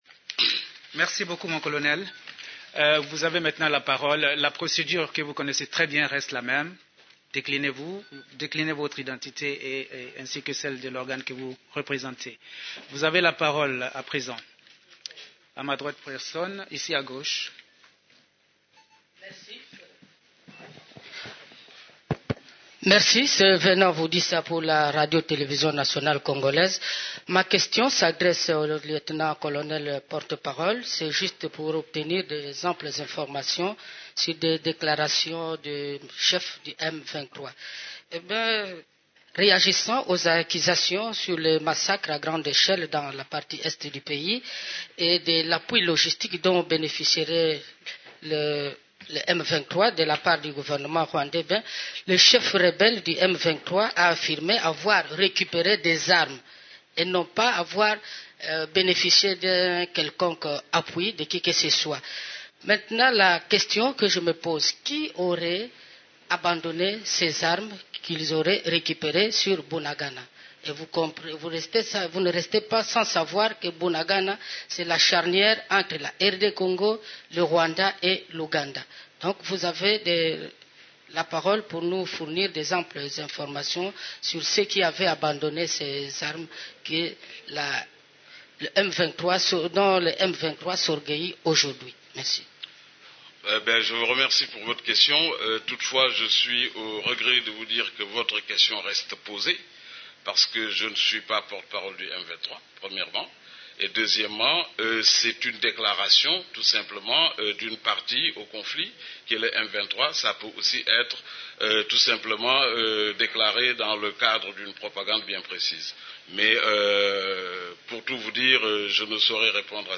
conference-de-presse-21.mp3